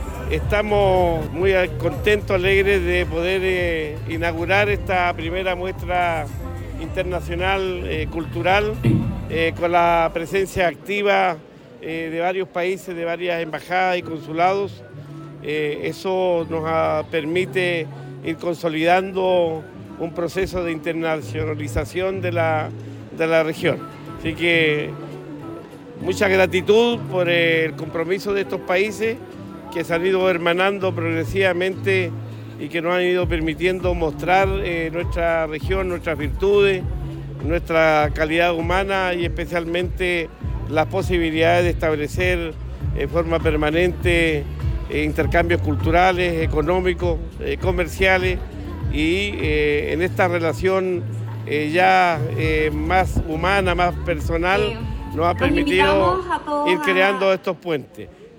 El gobernador regional Luis Cuvertino explicó que el encuentro del proceso de internacionalización de la Región de Los Ríos que impulsa su administración al que han respondido los países presentes en el evento, a quienes expresó su agradecimiento.
CUNA-GOBERNADOR-CUVERTINO.mp3